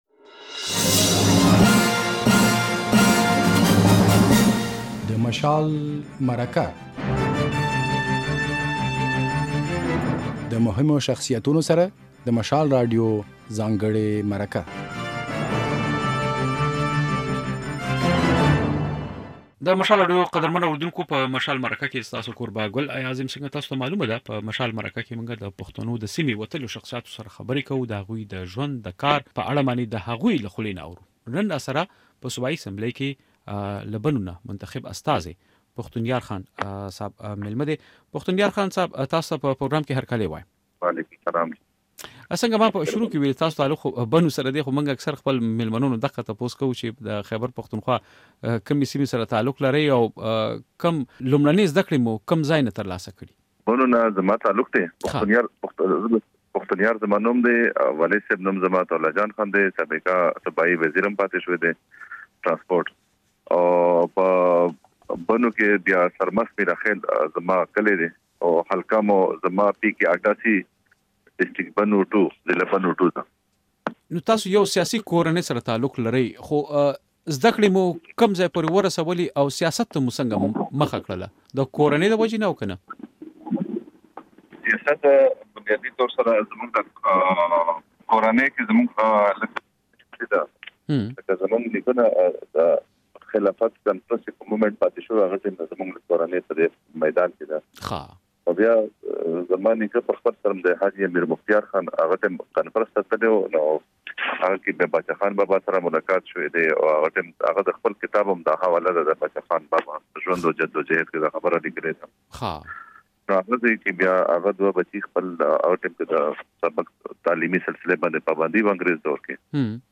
په مشال مرکه کې په صوبايي اسمبلۍ کې د واکمن تحریک انصاف ګوند غړی پښتون يار خان ميلمه دی.